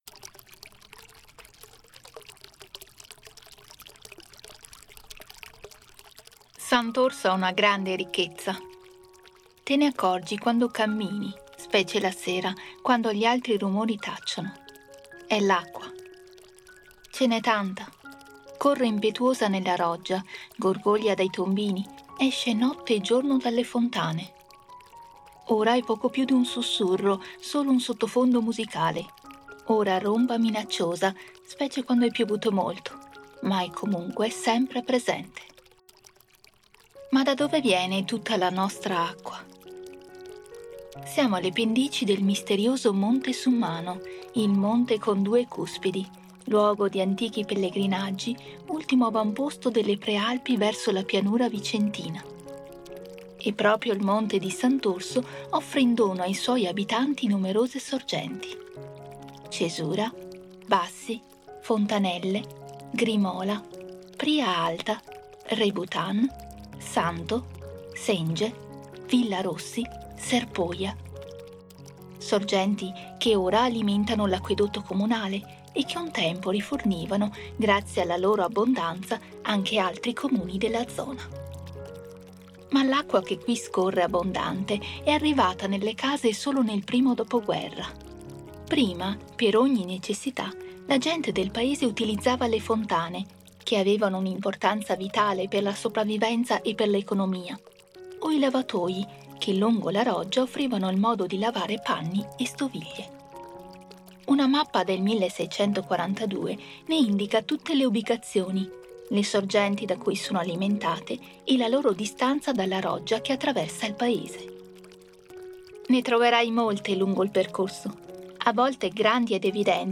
AUDIOGUIDA_Fontane._01._Santorso_paese_dell_acqua.mp3